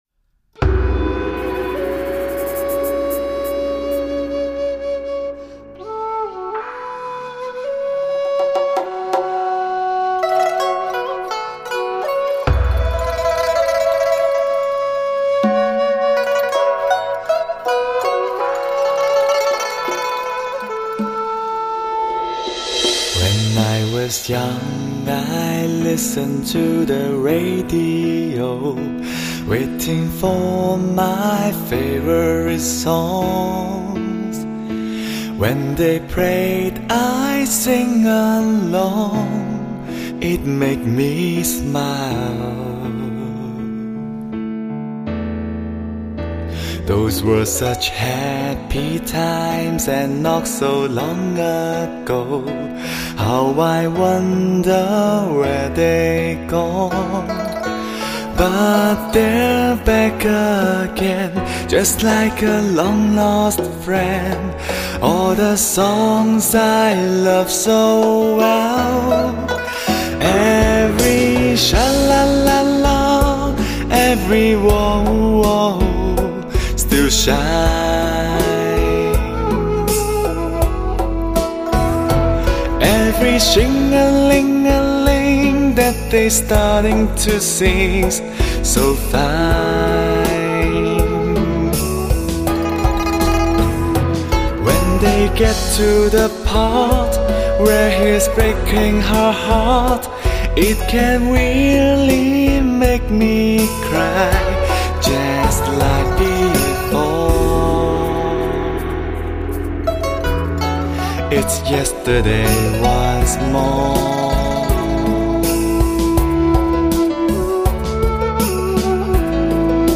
音乐类型: 天籁人声/POP